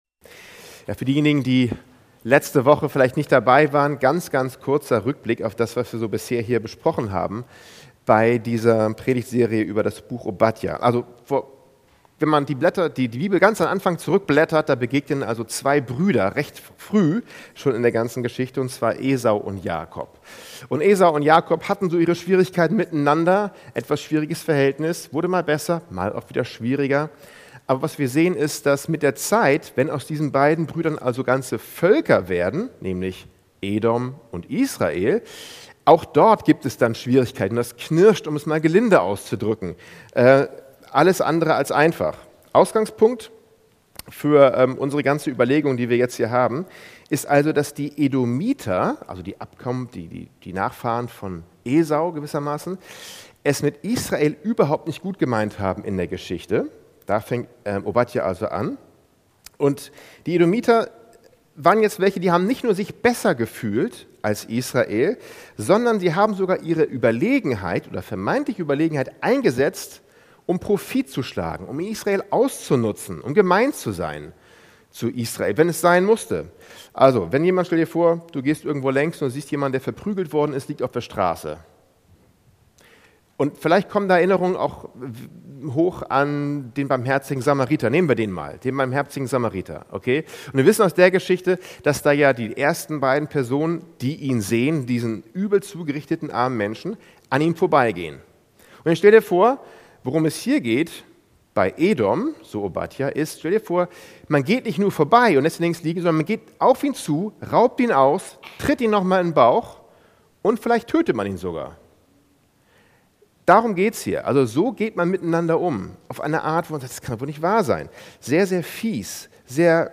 Dateien zum Herunterladen Update Predigt als MP4